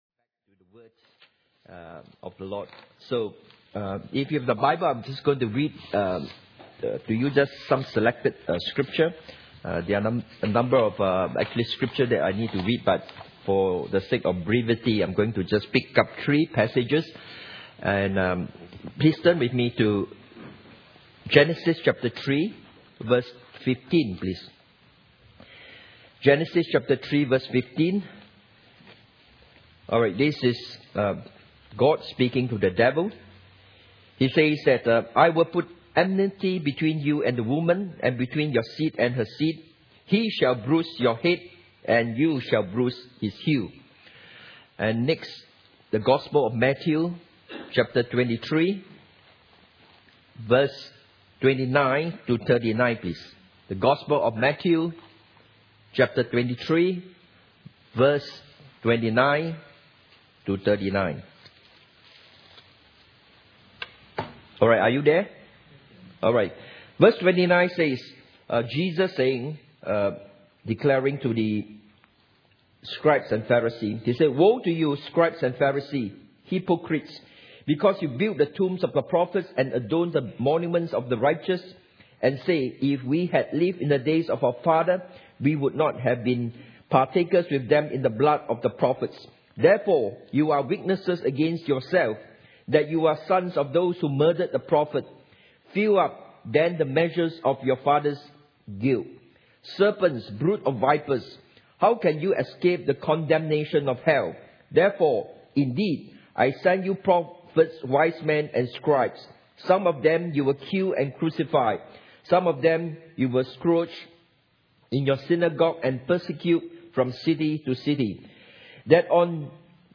Israel Service Type: Sunday Morning « How clean is your mirror Israel P7